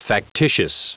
Prononciation du mot factitious en anglais (fichier audio)
Vous êtes ici : Cours d'anglais > Outils | Audio/Vidéo > Lire un mot à haute voix > Lire le mot factitious